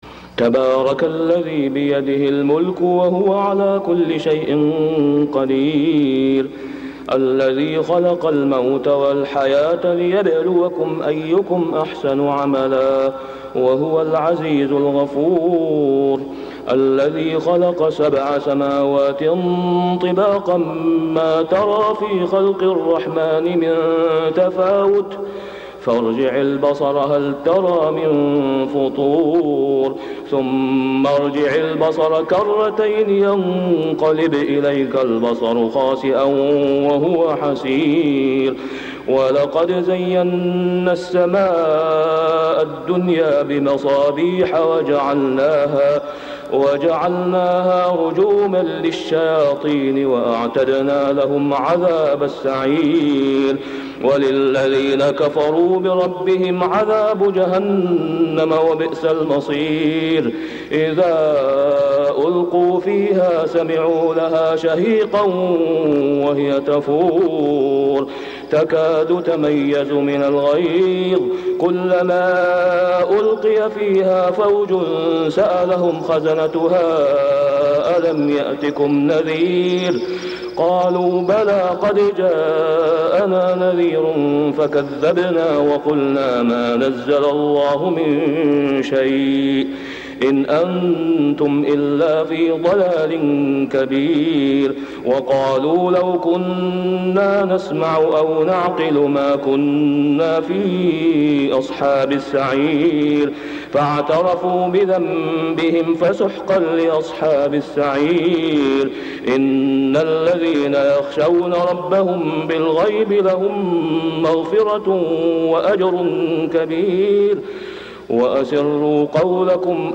سورة الملك > السور المكتملة للشيخ أسامة خياط من الحرم المكي 🕋 > السور المكتملة 🕋 > المزيد - تلاوات الحرمين